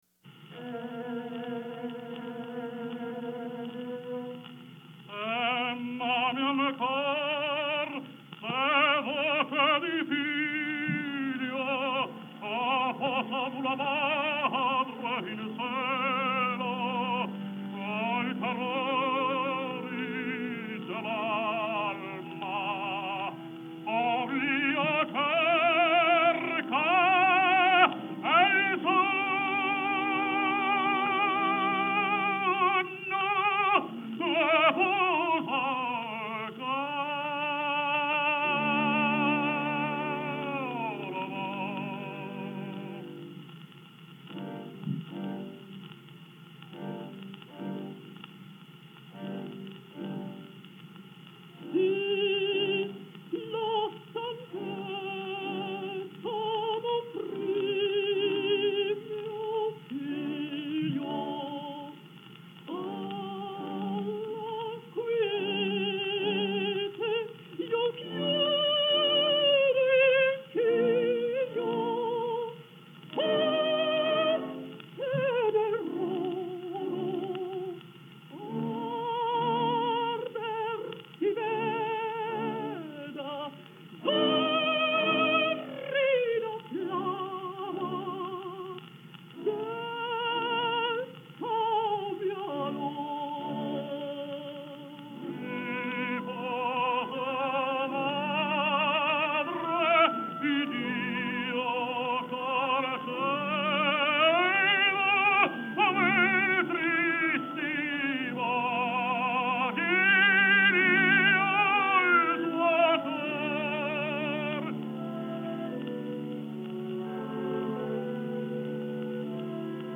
Жанр: Vocal